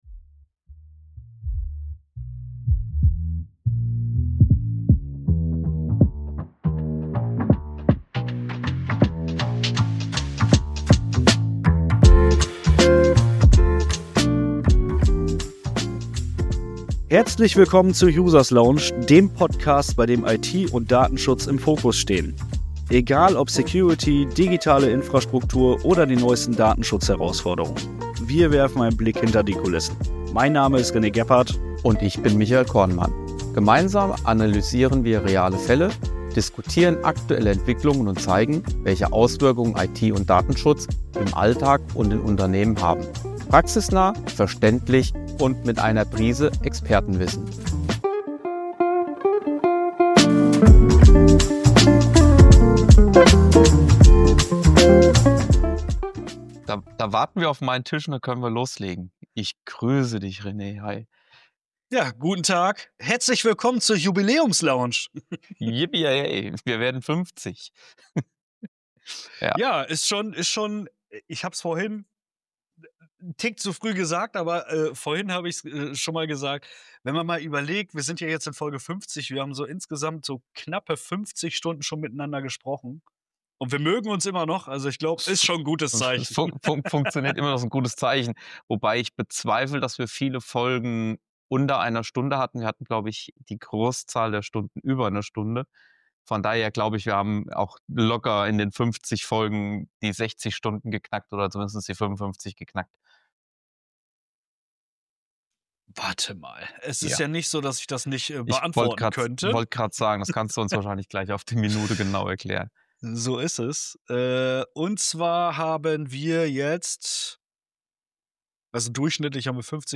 Wir führen einen lockeren Plausch über die vergangenen Episoden und besprechen, was bei uns persönlich von den Themen hängen geblieben ist, was sich bei uns in den 2 Jahren geändert hat usw.